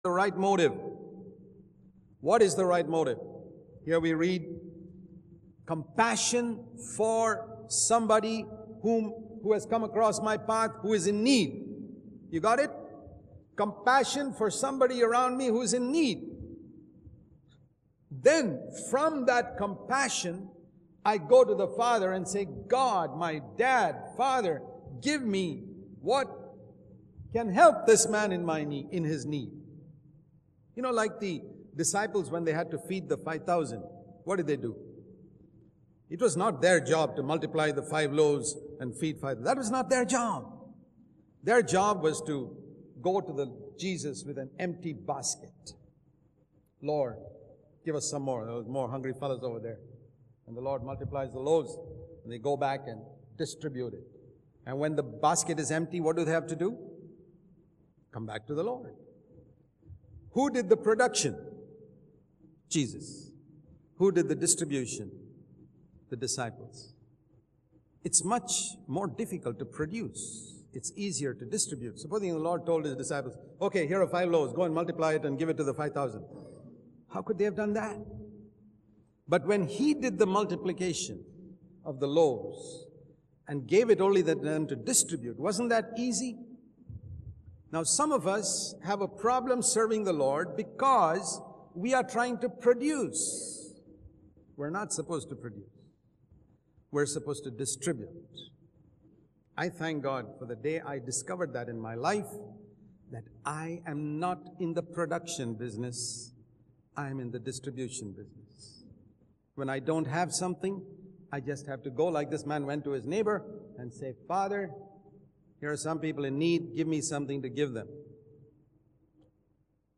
In this sermon, the preacher discusses three parables from the Bible.